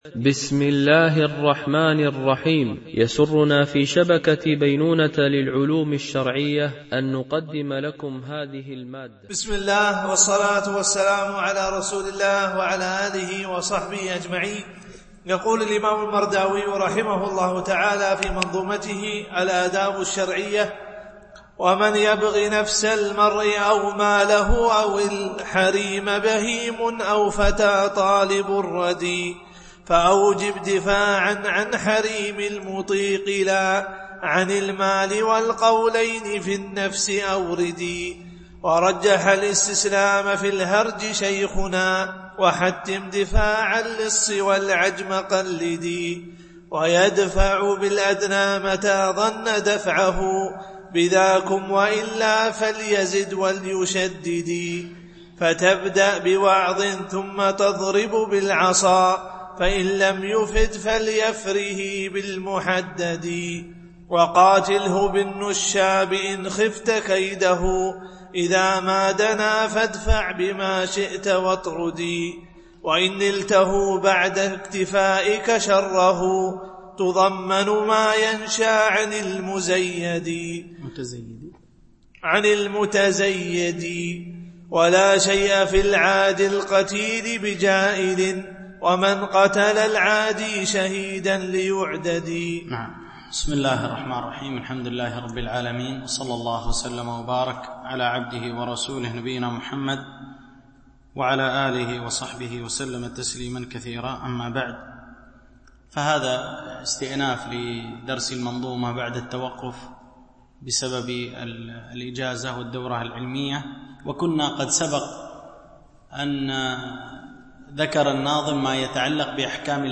شرح منظومة الآداب الشرعية – الدرس45 ( الأبيات 700-725 )